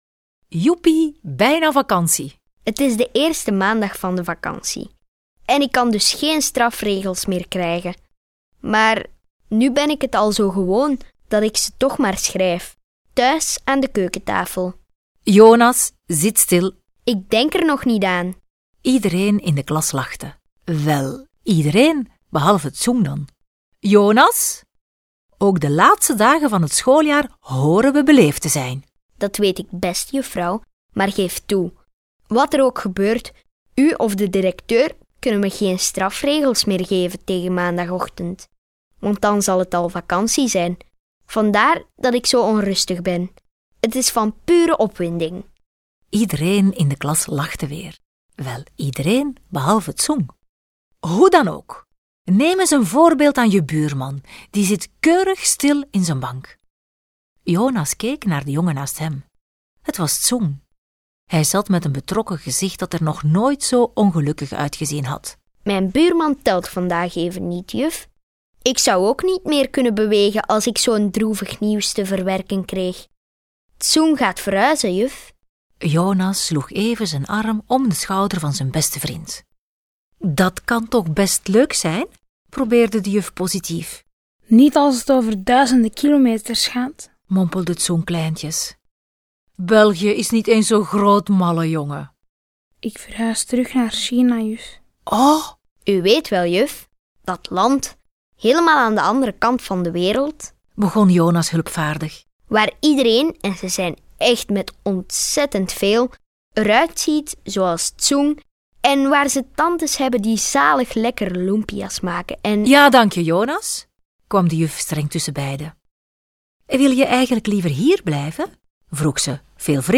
3) Neem je taalboek op p. 75 en luister samen met het geluidsfragment naar het LAATSTE taalkanjerverhaal.